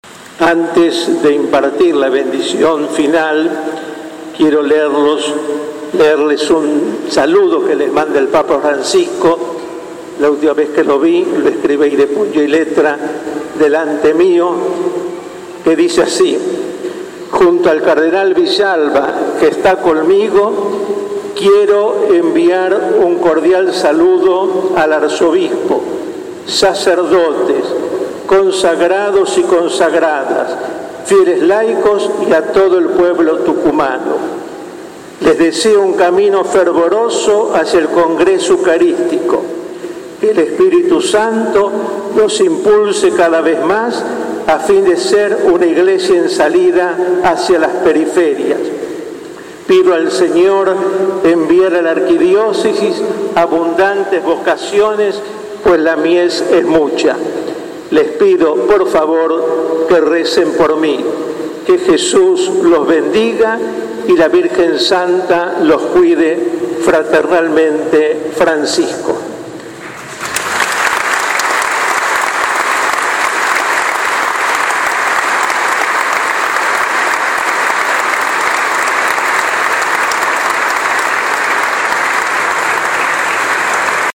En su homilía, monseñor Villalba explicó que la misión fundamental que les dio el papa Francisco a los cardenales es la de ser servidores de la Iglesia.